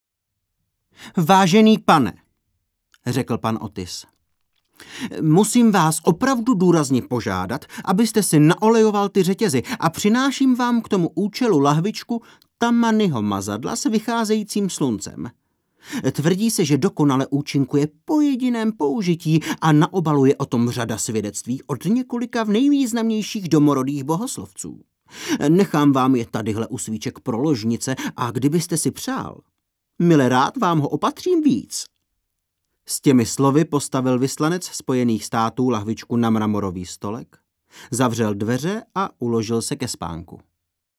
Audio knihy: